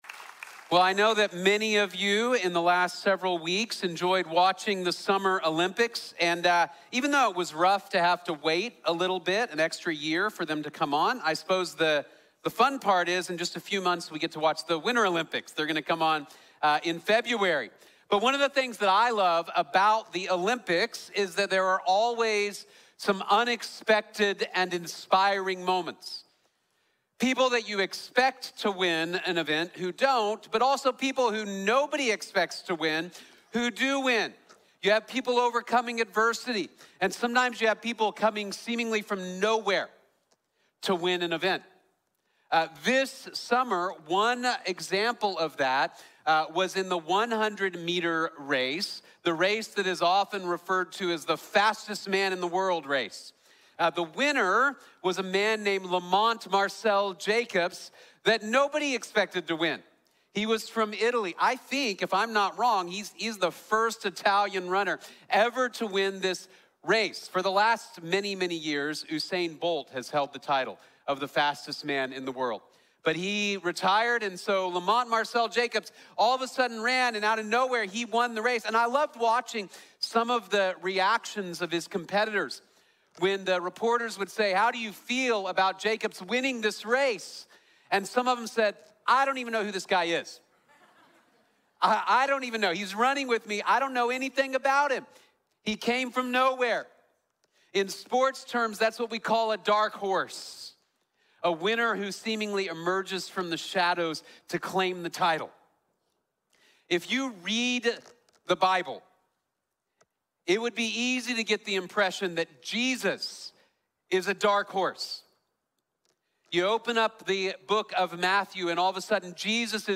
The King is Coming | Sermon | Grace Bible Church